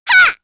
Hanus-Vox_Damage_02_kr.wav